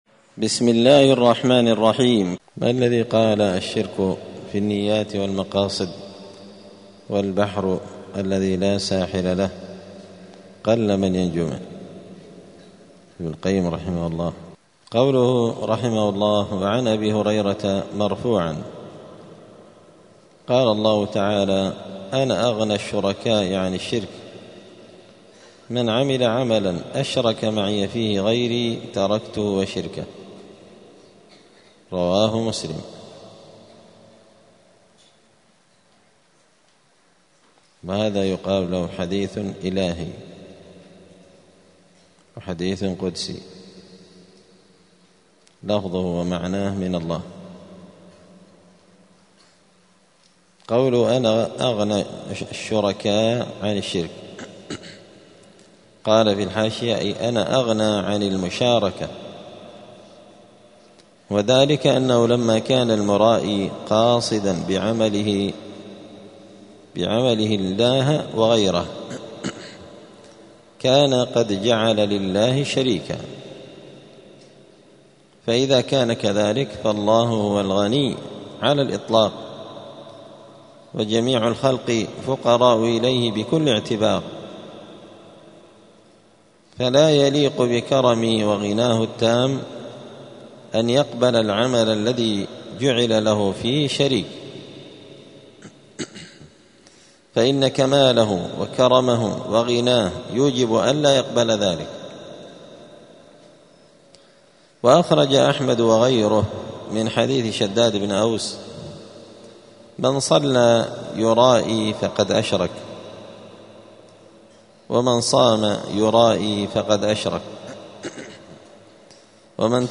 دار الحديث السلفية بمسجد الفرقان قشن المهرة اليمن
*الدرس الواحد بعد المائة (101) {تابع لباب الرياء}*